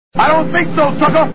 The A-Team TV Show Sound Bites